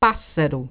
Click the button below to hear the pronunciation of the word
Imagine hearing a BIRD PASS A RUDE comment